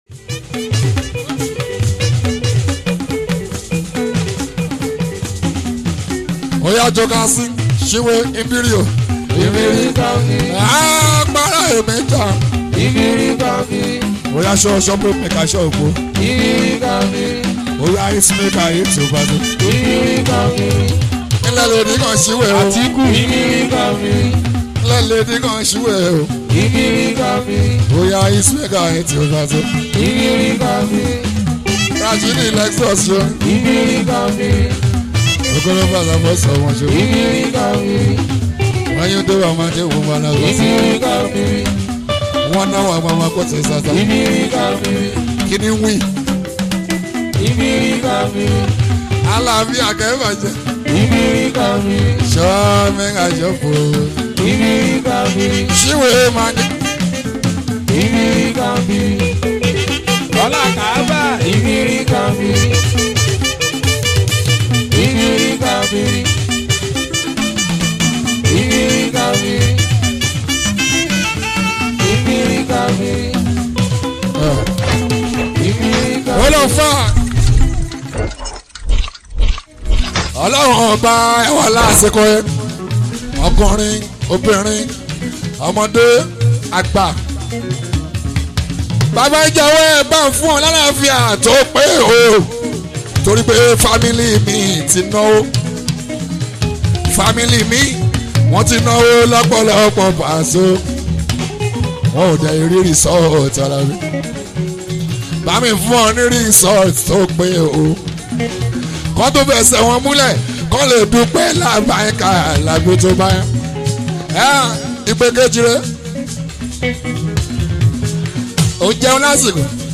Nigerian Yoruba Fuji track
put on your dancing shoes and be ready to dance to the beats